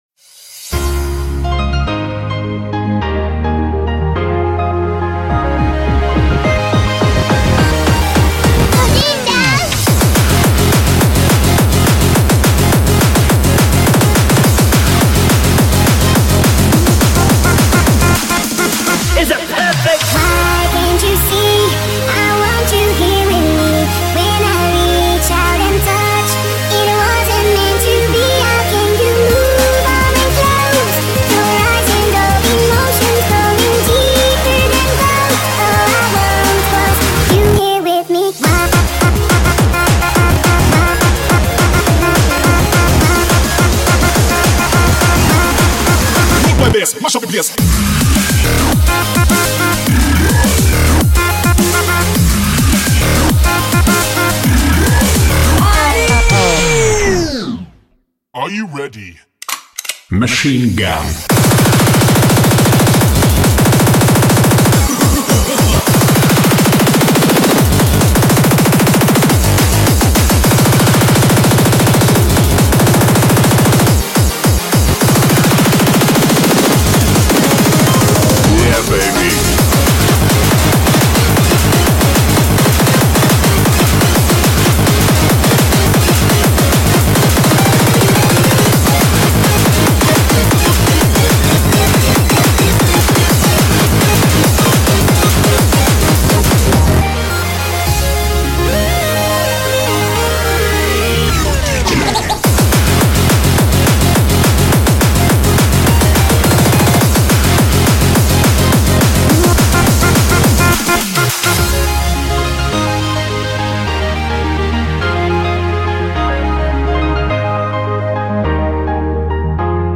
BPM105-210
Audio QualityPerfect (Low Quality)